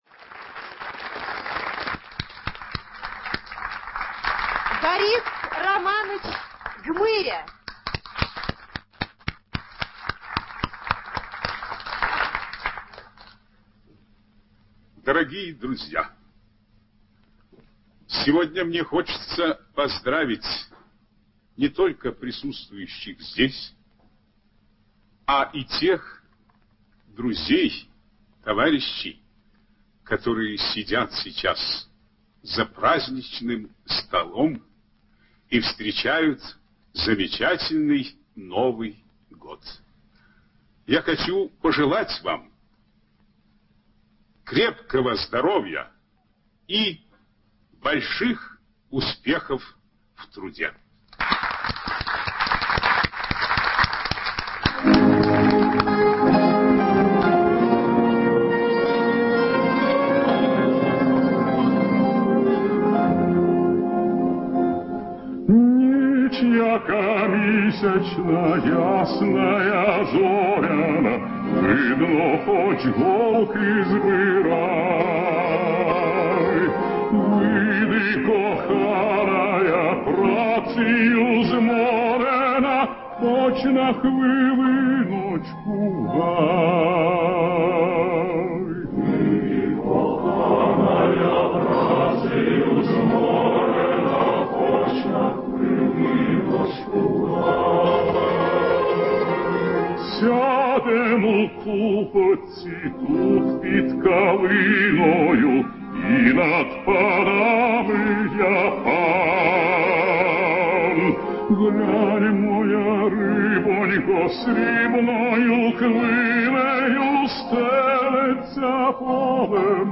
Романс «Hiч яка мiсячна» исполняет народный артист СССР Борис Гмыря.
Новогодний выпуск телепередачи «Голубой огонёк», 1962 год